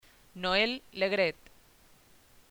Guía de Pronunciación BRASIL 2014
PresidenteNoel LE GRAETNoél Legrét